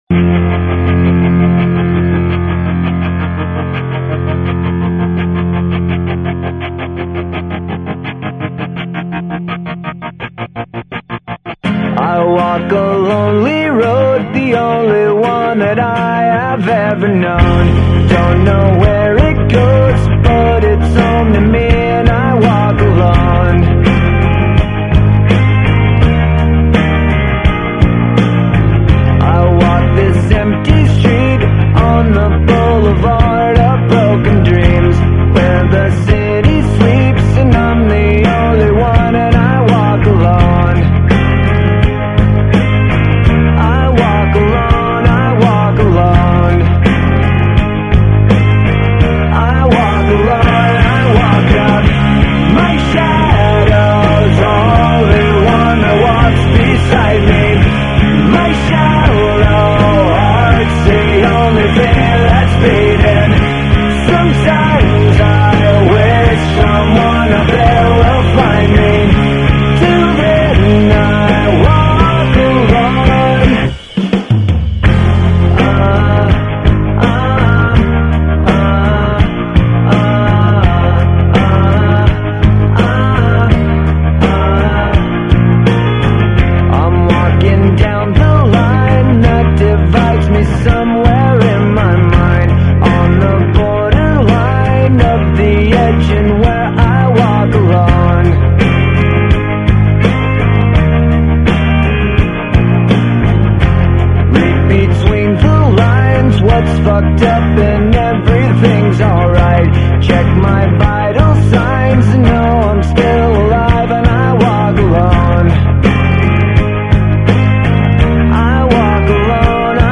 Drums
有點孤獨,狂傲(搖滾人的特質)